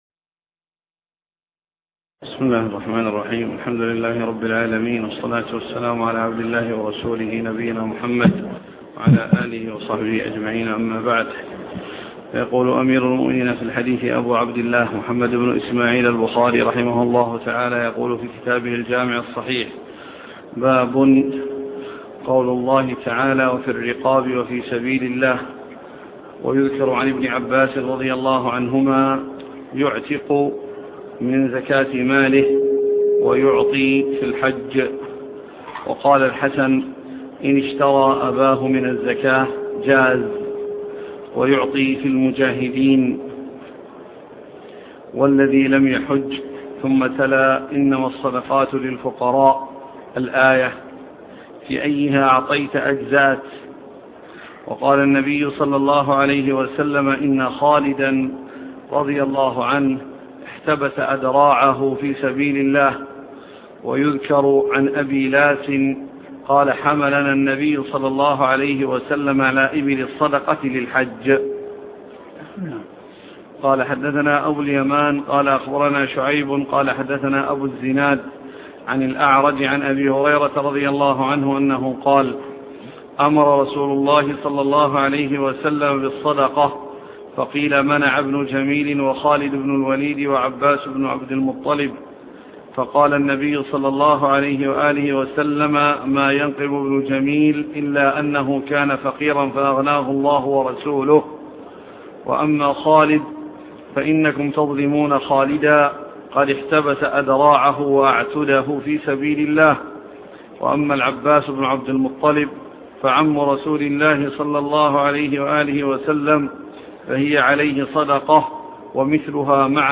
شرح صحيح البخاري الدرس عدد 181